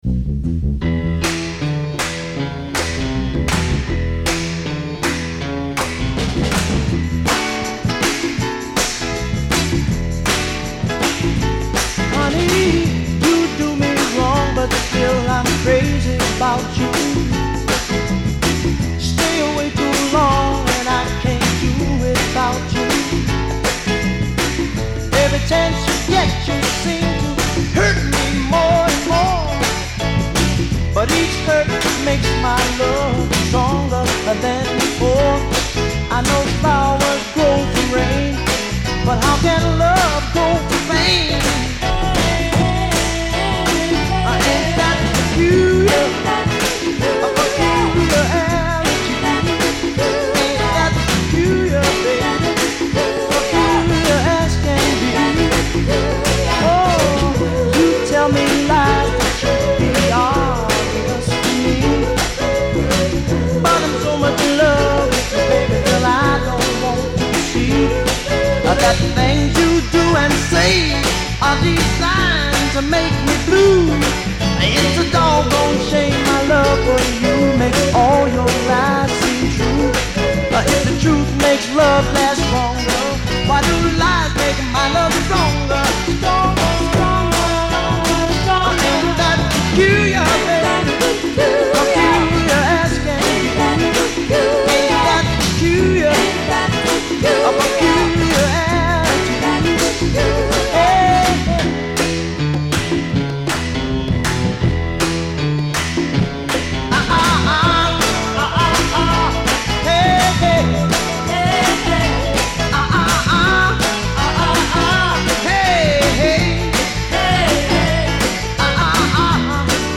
Listen to the bassline, ’nuff said.